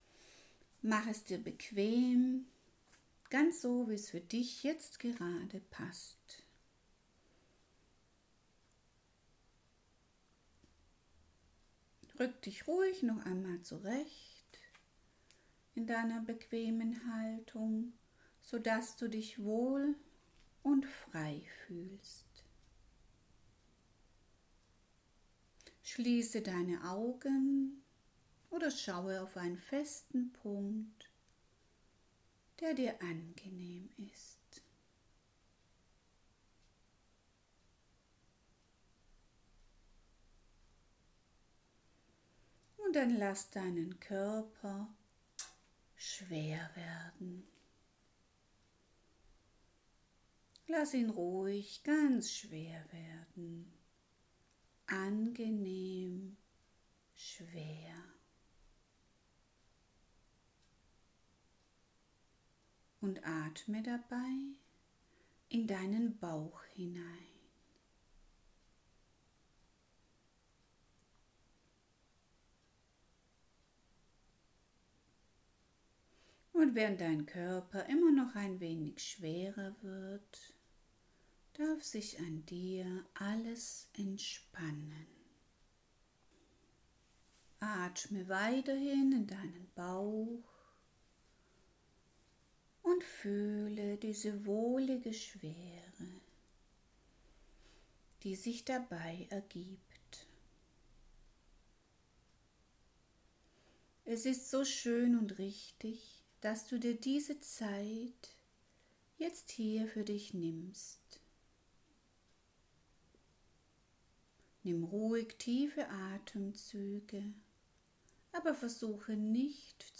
Abendmeditation.mp3